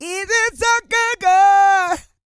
E-GOSPEL 223.wav